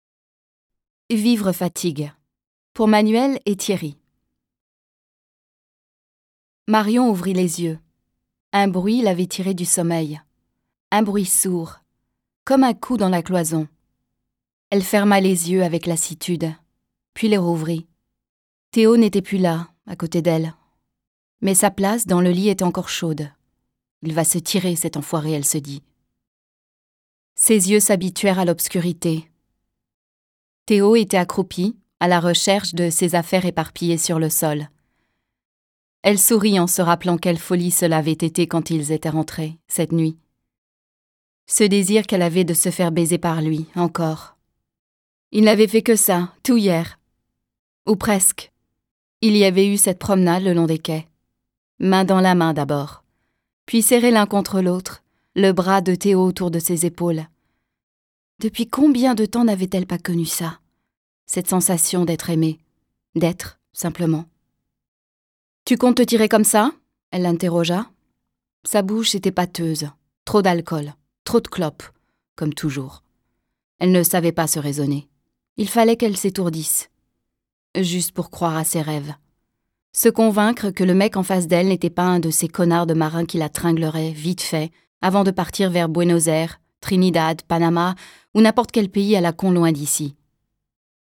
Das Hörbuch zum Sprachen lernen.Ungekürzte Originalfassung / Audio-CD + Textbuch + CD-ROM
Interaktives Hörbuch Französisch